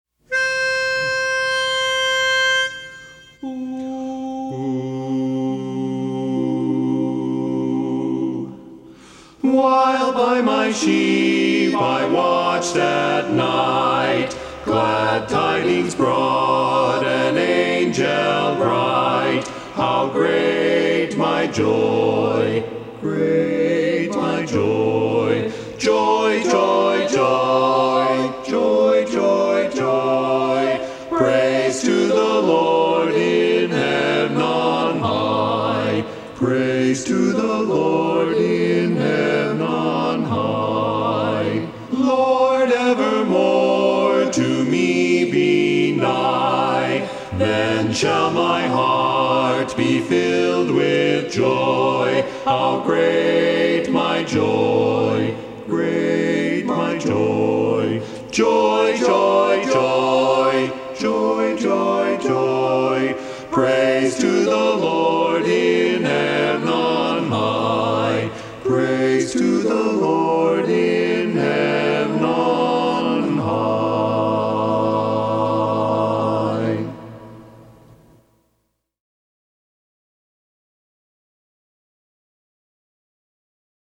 Christmas Songs
Barbershop
Bass